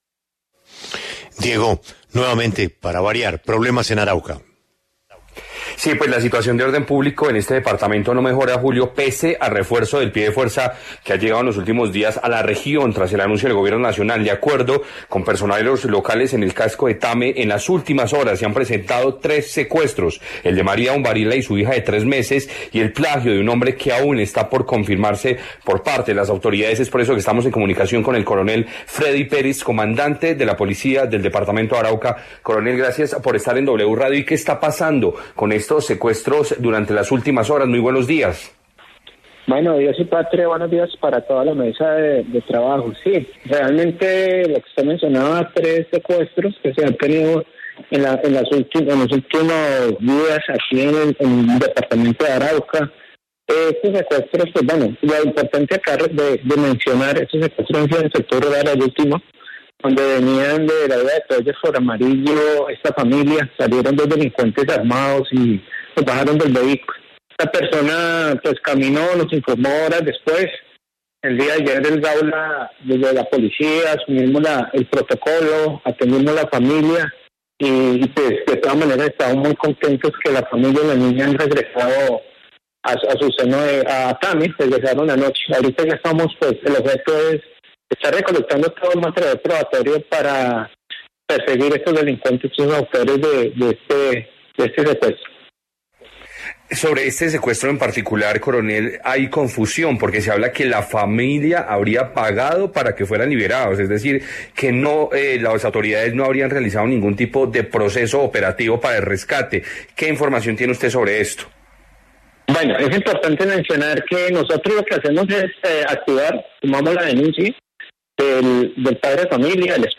Habló en La W el Coronel Fredy Perez comandante policía Arauca